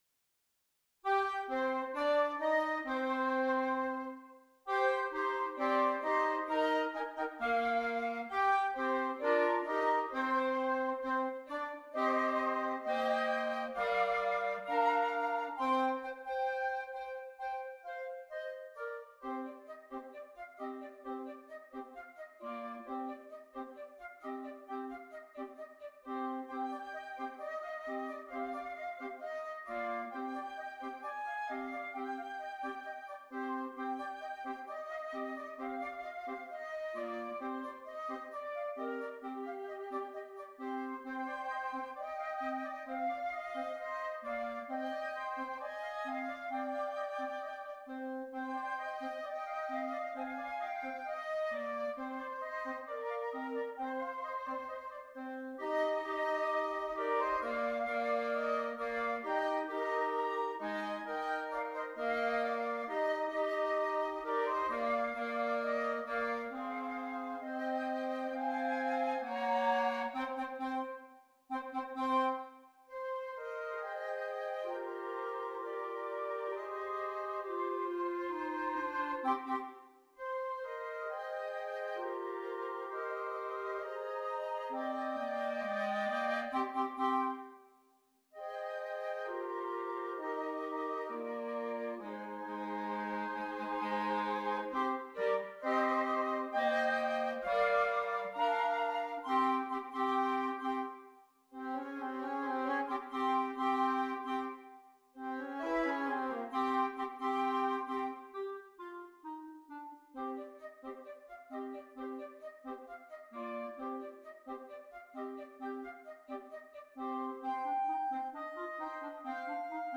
2 Flutes, 2 Clarinets
The music is always moving forward-advancing.